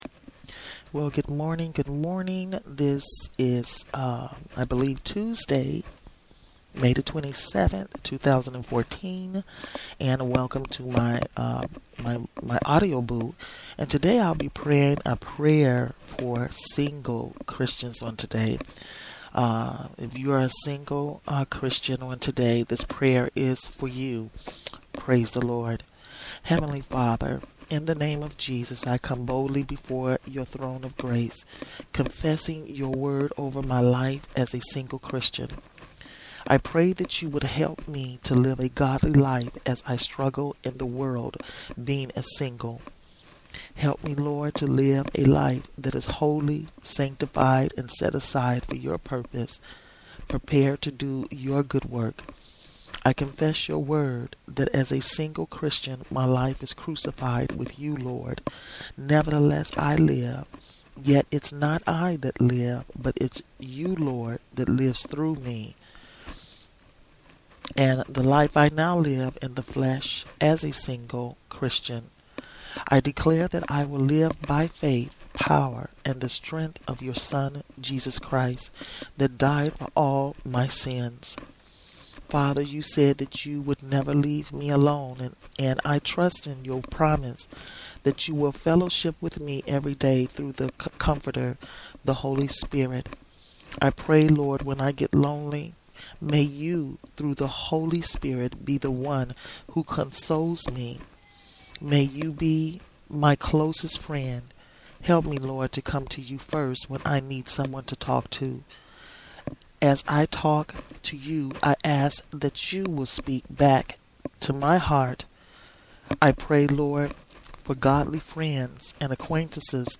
This is a simple prayer for single Christians.